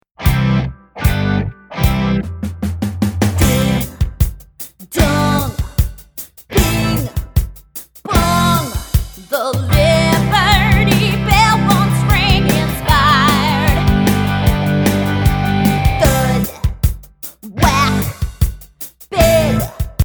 Downloadable Musical Play with Album Sheet Music.